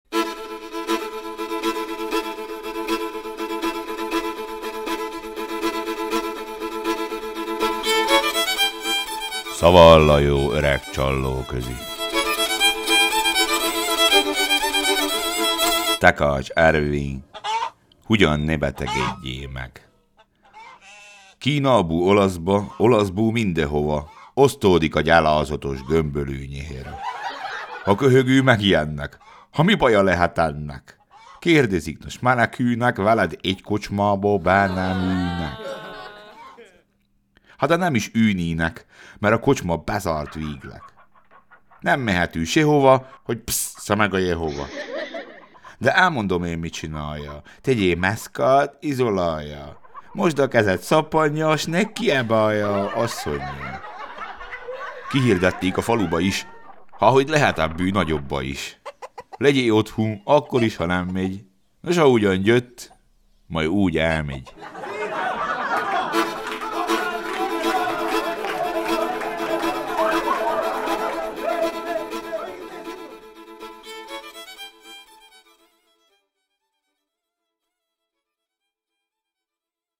Mára viszont pont jut egy, hiszen útjára indul a csallóközi szlengköltészet.
Fogadjátok szeretettel az első versikét: